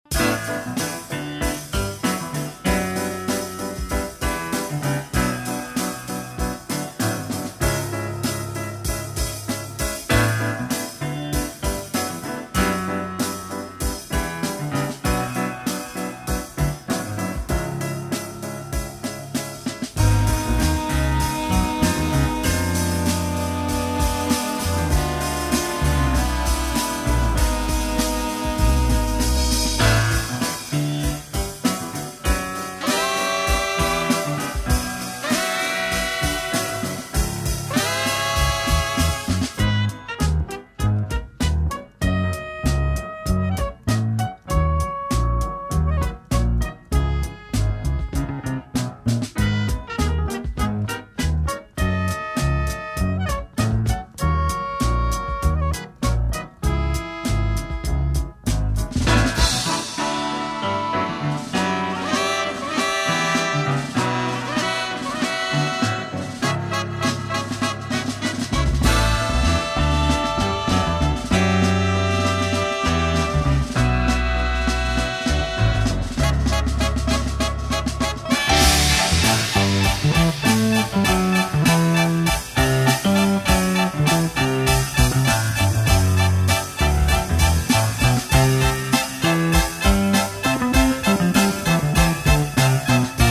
newly remastered